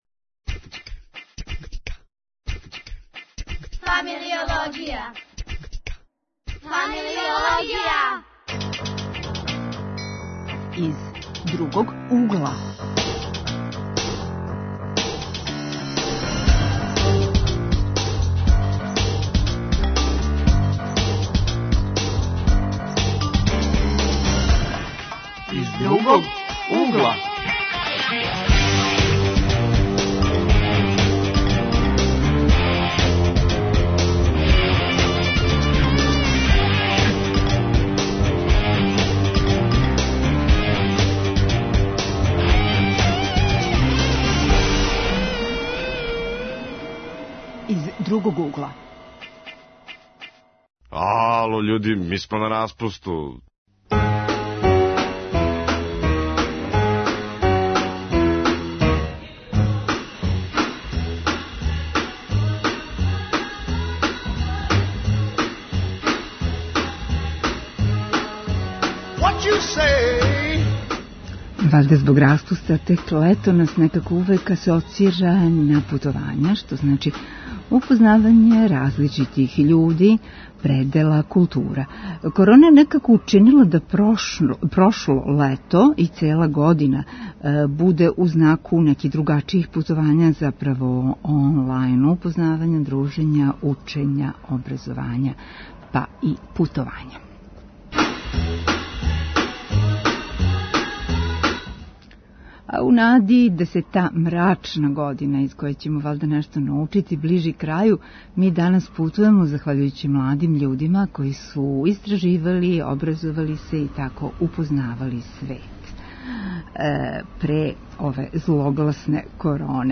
Гост у студију